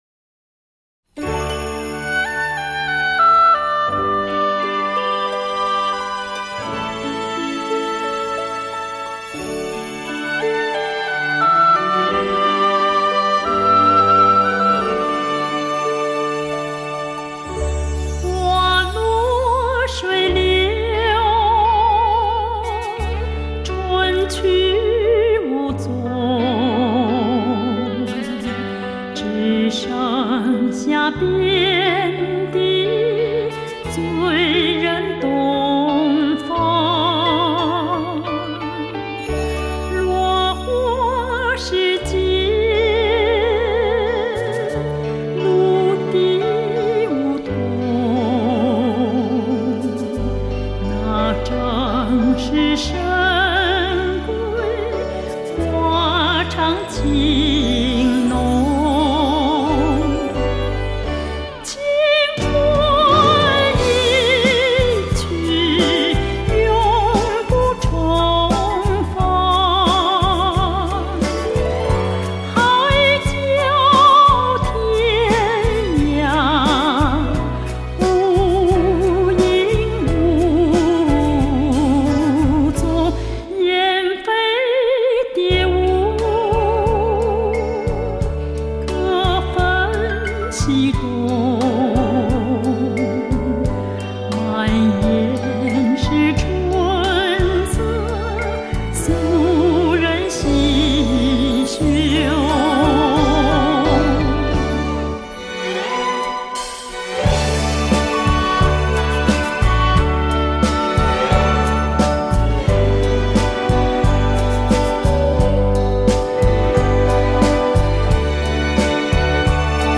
由于本片系针对音响迷需求而特别设计，所以伴奏非常丰富，立体感鲜明，动态与定位绝佳。
音质淳厚甜美，仿佛是一张黑胶唱片的靡靡之音，把我们带入三十年代的十里洋场。